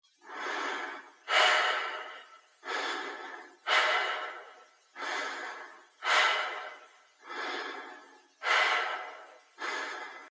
Oxygen Mask Breaths
yt_dY2HT2XGF5A_oxygen_mask_breaths.mp3